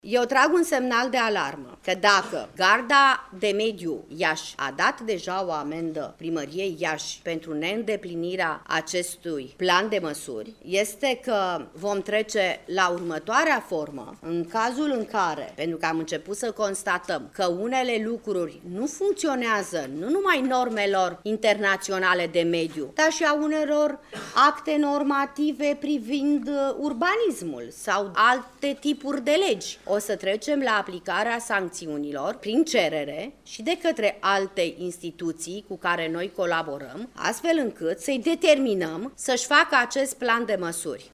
Ministrul mediului, Graţiela Gavrilescu, a mai precizat că în cazul Iaşului va fi urmărită şi situaţia din domeniul urbanismului deoarece a fost avizată începerea unor construcţii fără a fi îndeplinite şi condiţiile de mediu conform cărora şantierele trebuie împrejmuite, iar autovehiculele folosite în construcţii trebuie spălate la ieşirea din perimetrul respectiv: